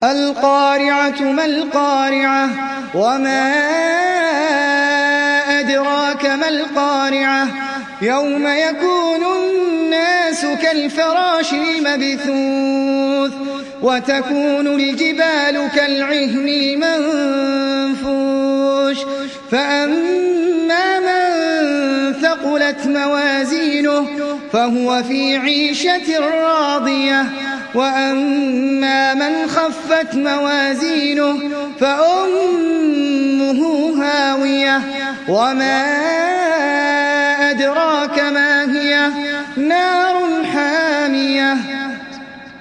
دانلود سوره القارعه mp3 أحمد العجمي روایت حفص از عاصم, قرآن را دانلود کنید و گوش کن mp3 ، لینک مستقیم کامل